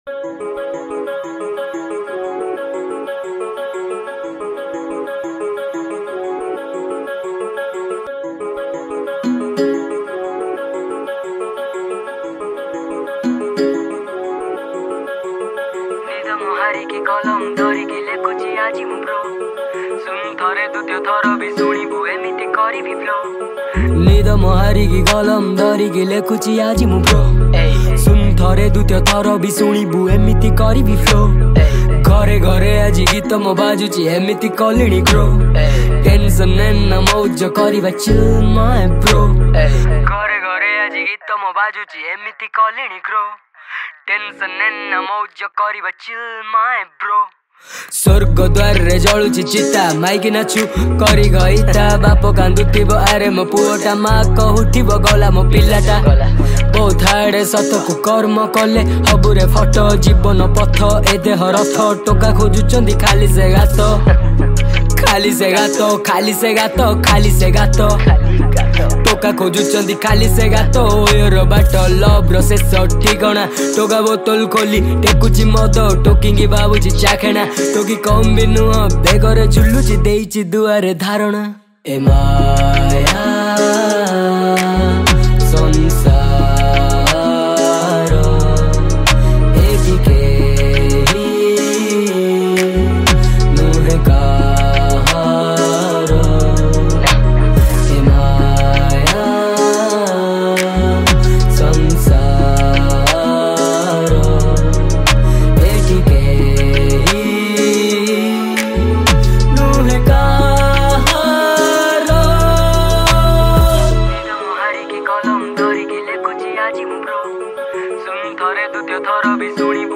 Odia Rap Song